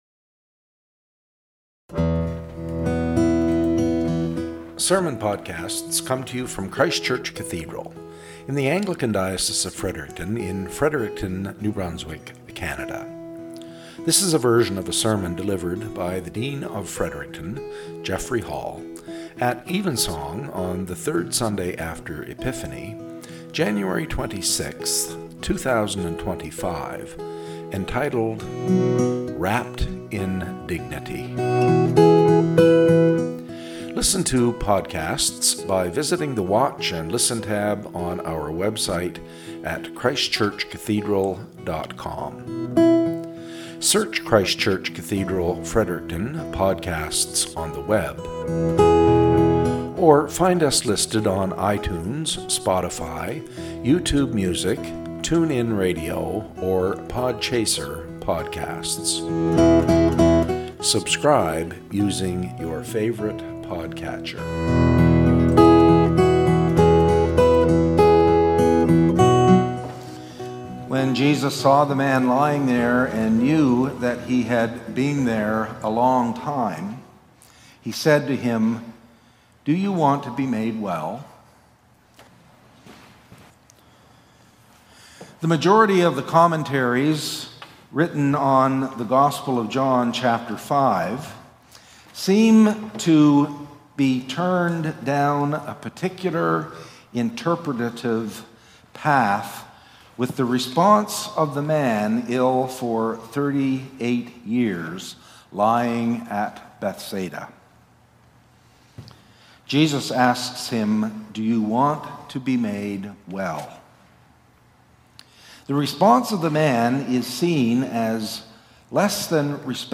Podcast from Christ Church Cathedral Fredericton
SERMON - "Wrapped in Dignity"